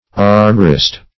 Search Result for " armorist" : The Collaborative International Dictionary of English v.0.48: Armorist \Ar"mor*ist\, n. [F. armoriste.]